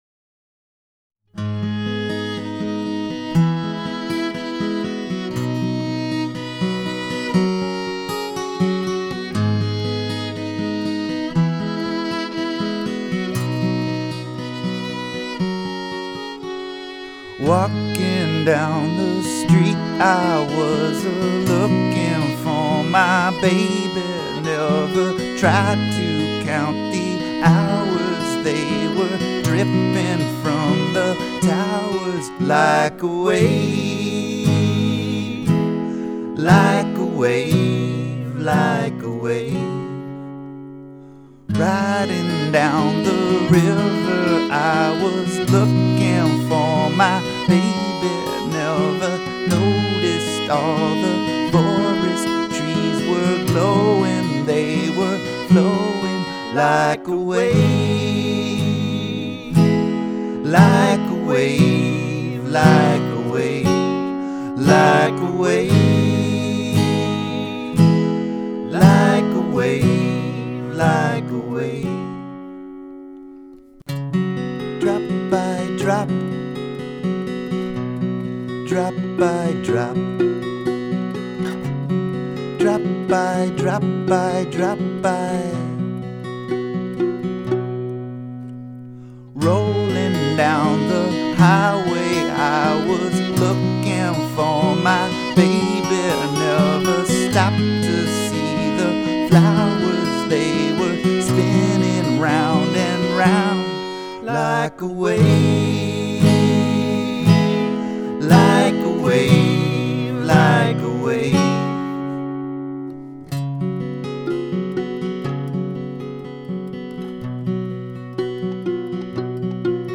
Guitar and vocals
Mandolin, fiddle, and vocals
Tabla and percussion